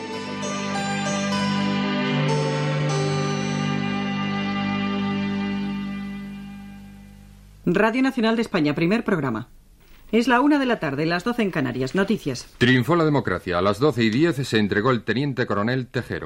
Identificació del Primer Programa de Radio Nacional de España, hora i titular: a les 12h10 s'ha lliurat el tinent coronel Antonio Tejero
Informatiu